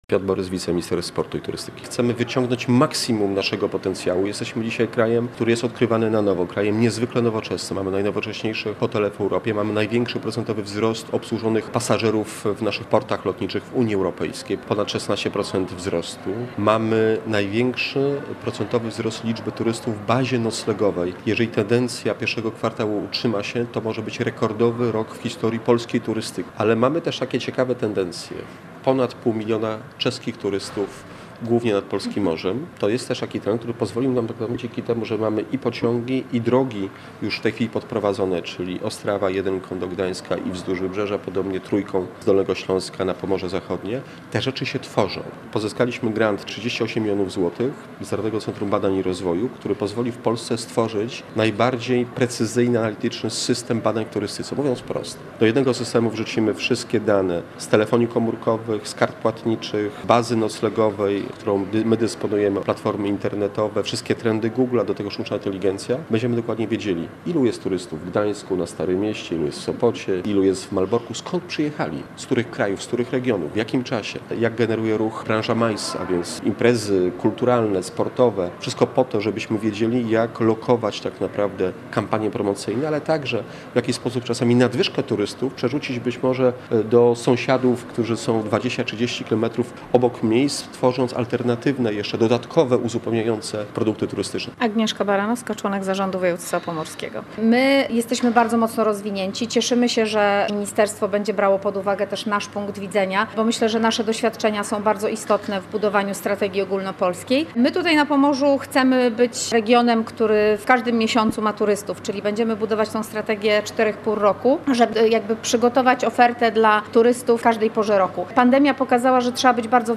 Budowanie wizerunku regionu i zwiększanie zainteresowania Polską – między innymi o tym rozmawiają eksperci w Gdańsku podczas konferencji poświęconej wspólnej strategii rozwoju turystyki.